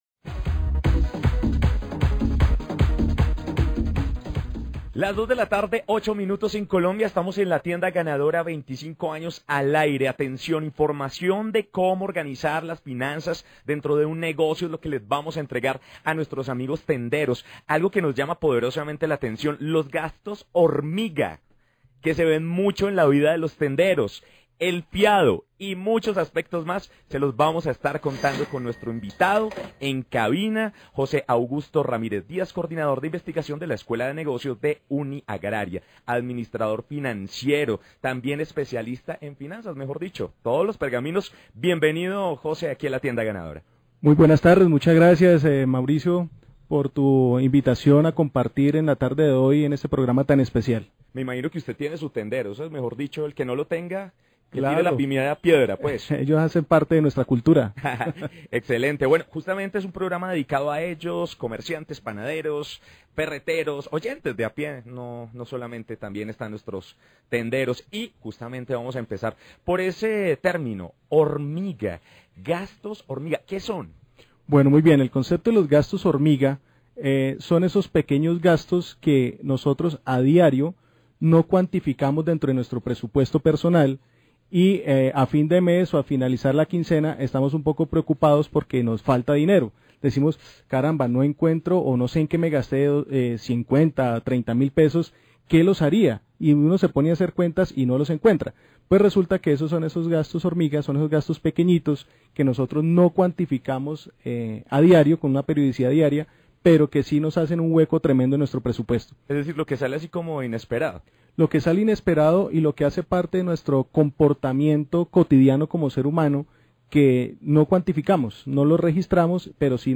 Entrevista en RCN Radio